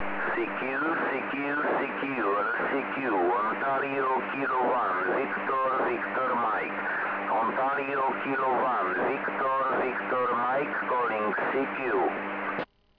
Pomocníkem je opakovač, který opakuje výzvu za vás. Jednoduché a levné řešení nabízí obvod ISD 1420, který lze pořídit za pouhých 200,- Kč.